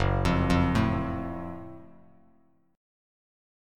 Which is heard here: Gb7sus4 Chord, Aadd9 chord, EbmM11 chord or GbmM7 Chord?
GbmM7 Chord